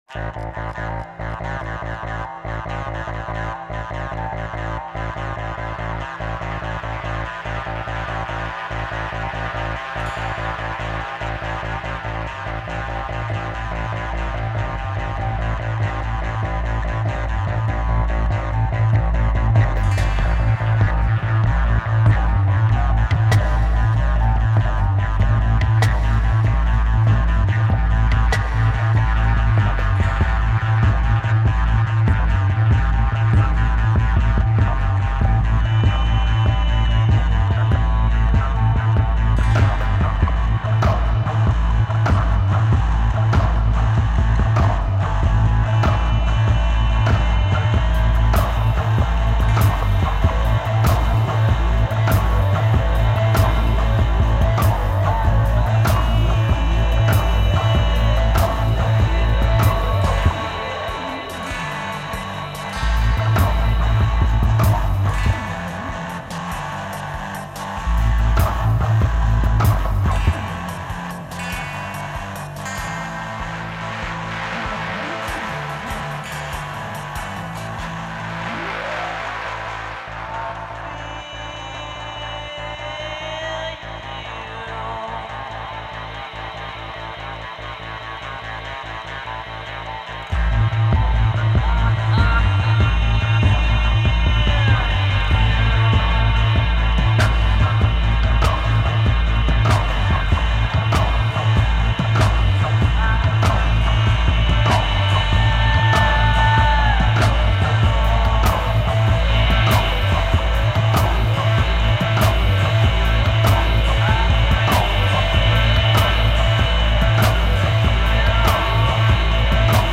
all the tracks are sung in English!
etc. (electronic-pop-experimental-rock-ambient)
(COVER)